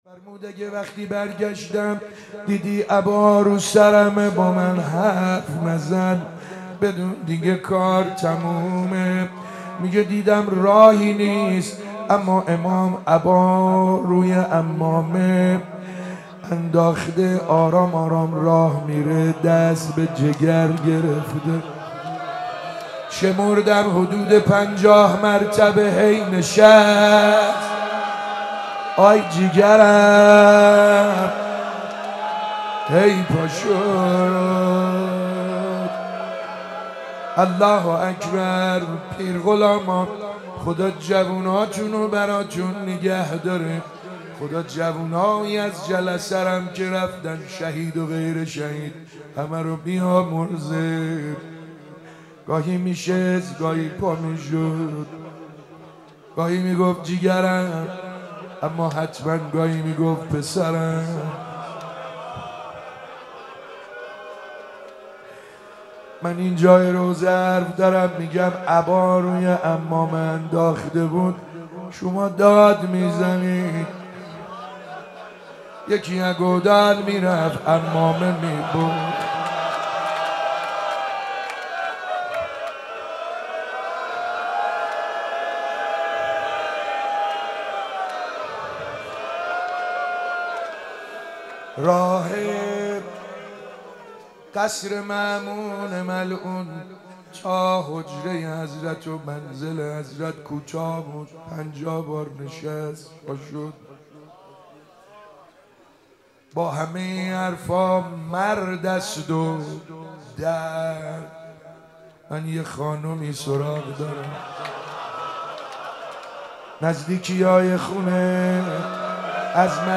شهادت امام رضا علیه السلام96 - روضه - امام رضا علیه السلام